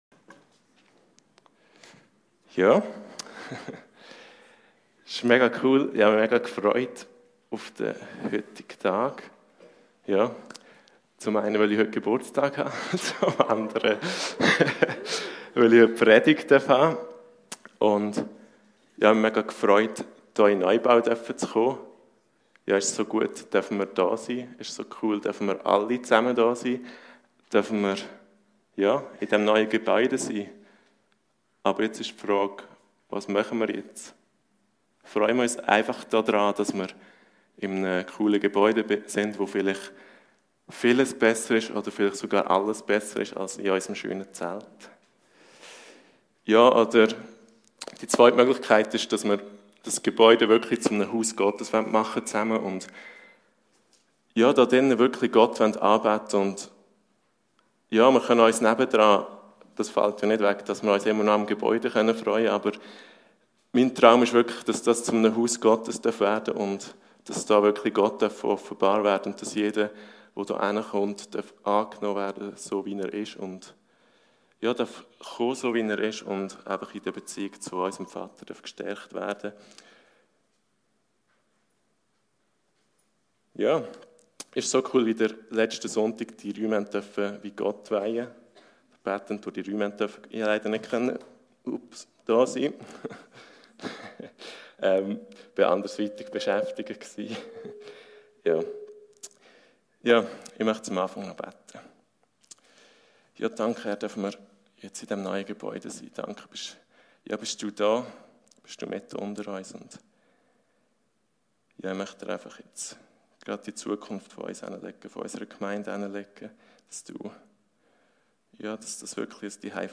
Predigten Heilsarmee Aargau Süd – Zeichen und Wunder